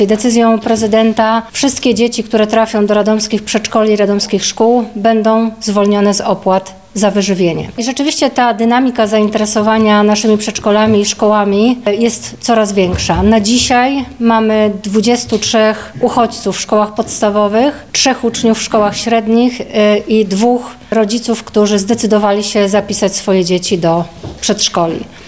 Mówi wiceprezydent, Katarzyna Kalinowska: